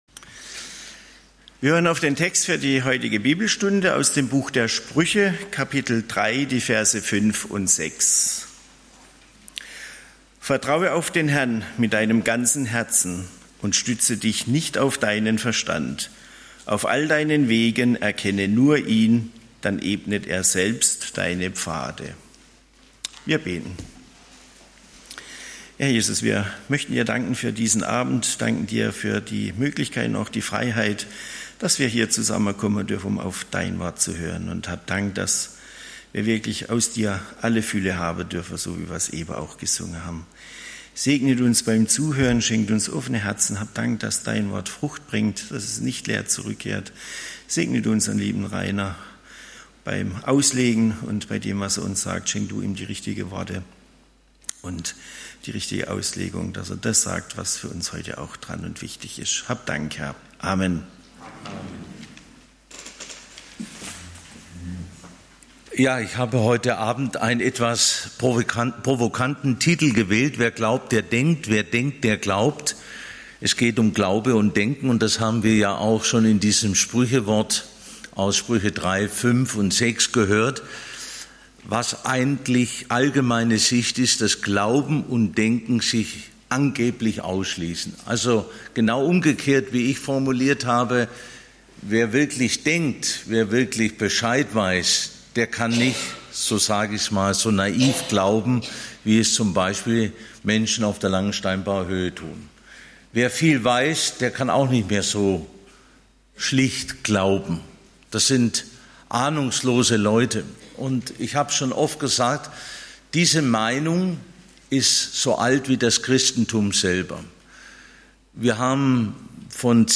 Paulus und seine Mitarbeiter: Erste Missionsreise, die Aussendung (Apg. 13, 1-4) - Gottesdienst